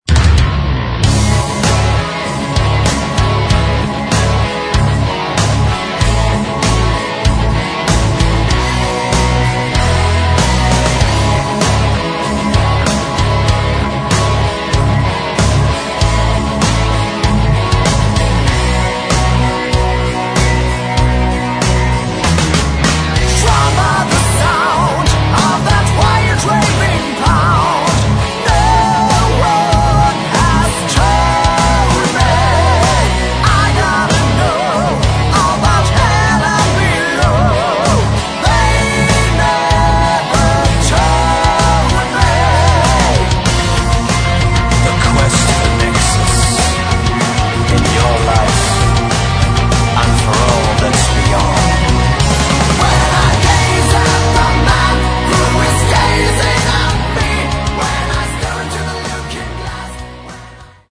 гитары
бас
ударные
клавишные